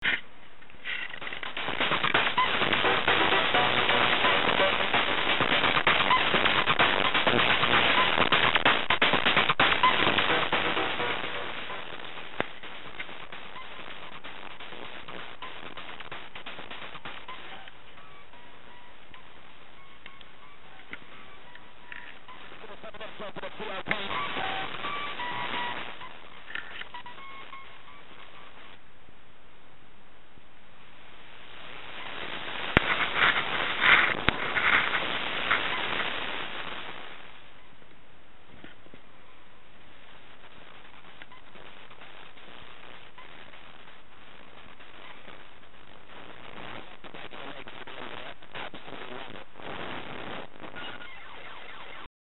Recording a signal that at first could not be heard or seen.
Top left to right circle: analogue radio, mini transmitter: 80hz to 12,500Hz, “Sonic Ear” and digital recorder.
In this experiment only the transmitter (top right of diagram. The receiver was used only to test to see if the signal was transferred wirelessly) was used and again it transmitted from 80 to 12,500Hz.
This recording was not heard by the human ear, but it was present and captured by the “sonic ear”.
radio-frequency-capture1.mp3